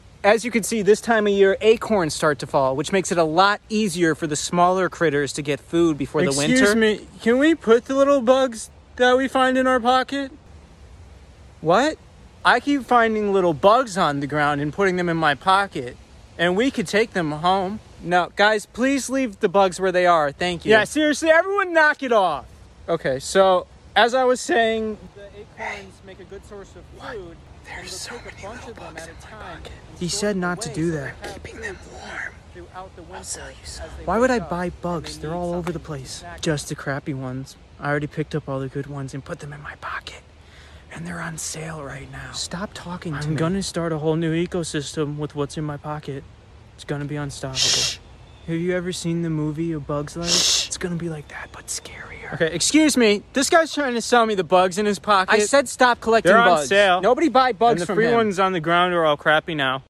Autumn Walk In The Woods Sound Effects Free Download